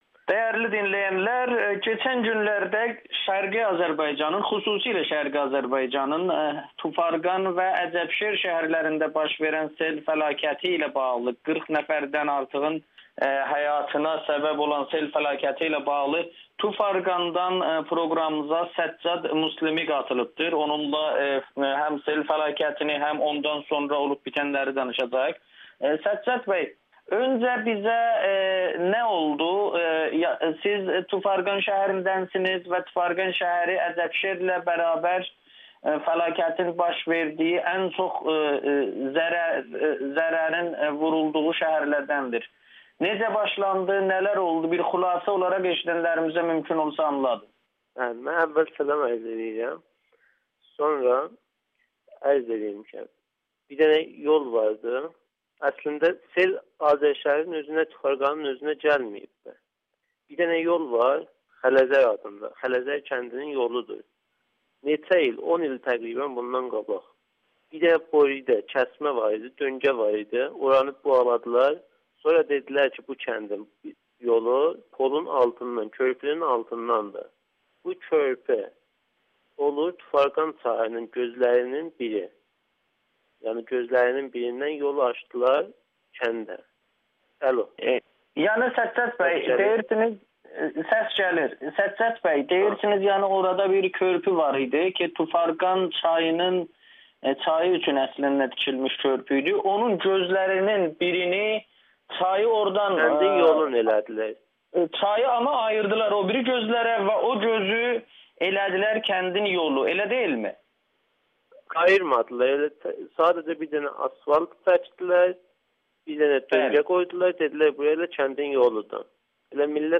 müsahibədə baş verənləri ətraflı şəkildə şərh edib.